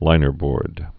(līnər-bôrd)